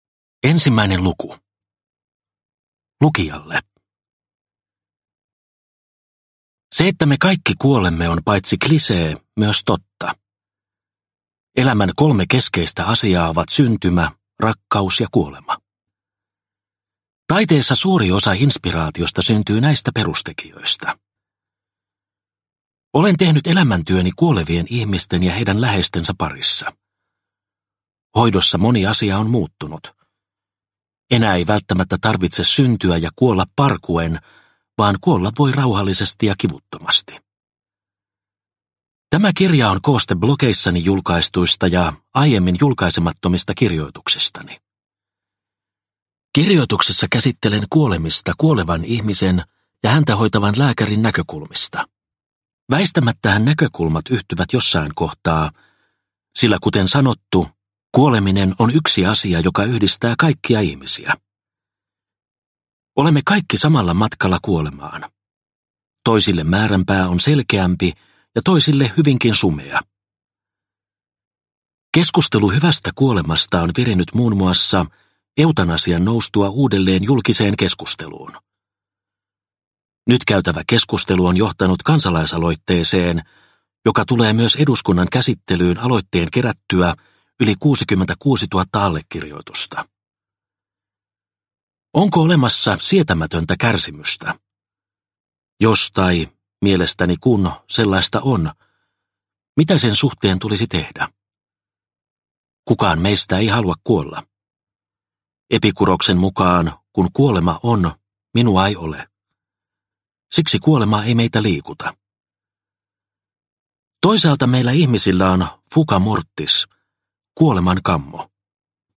Kuolemme vain kerran – Ljudbok – Laddas ner